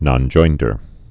(nŏn-joindər)